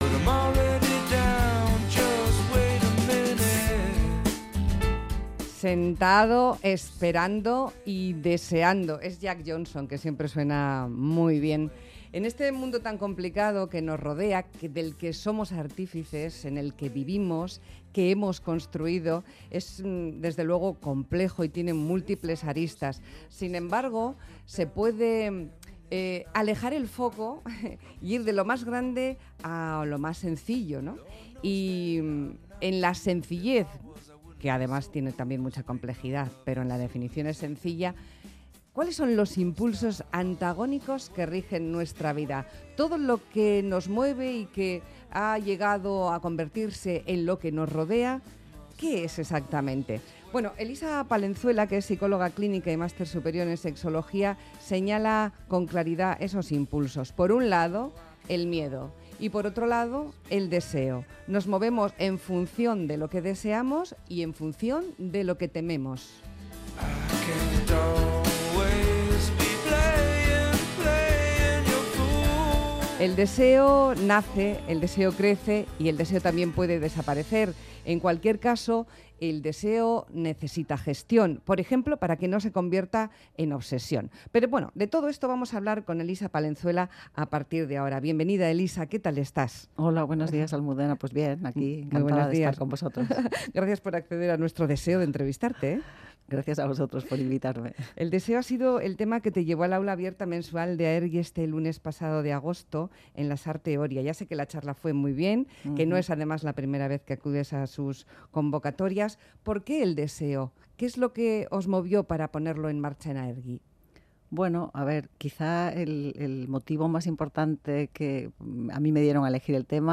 psicóloga clínica, habla de los secretos del deseo.